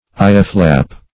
Eyeflap \Eye"flap"\